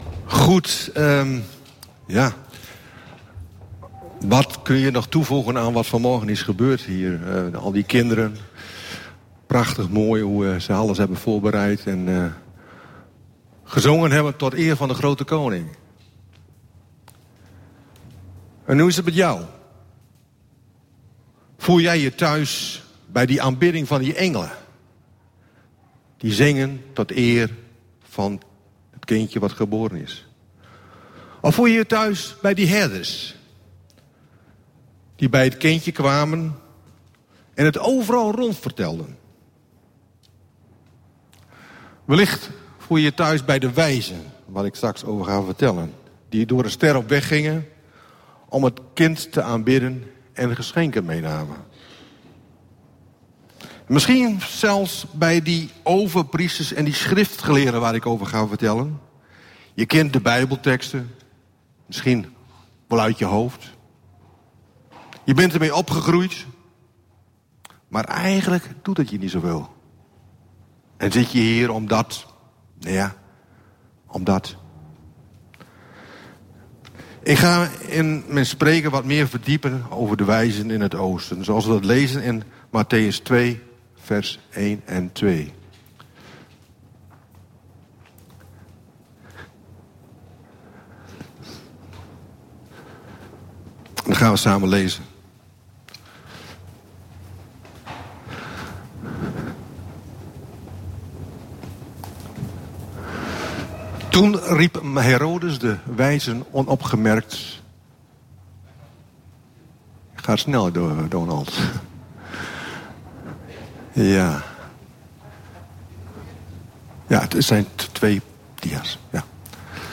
Luister hier gratis 200+ audio-opnames van preken tijdens onze evangelische diensten en blijf verbonden met Jezus!